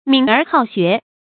敏而好学 mǐn ér hǎo xué
敏而好学发音